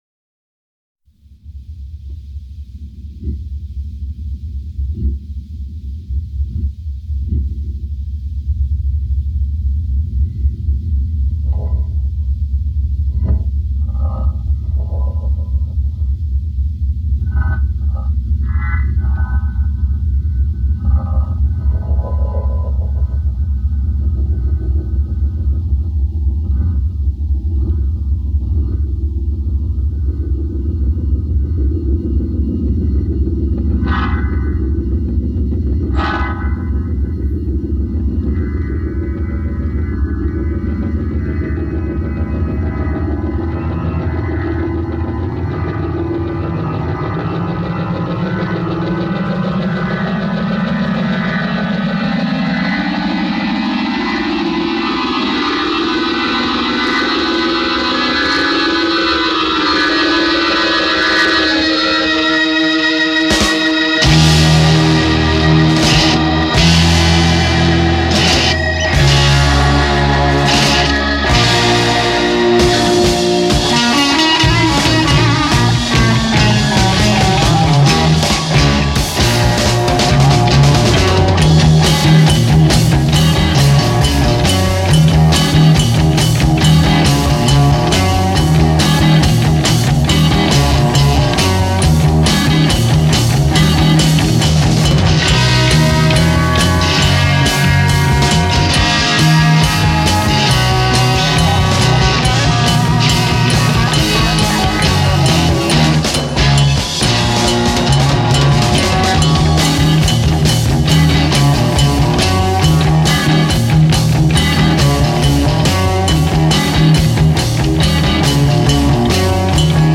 영국 하드 록 밴드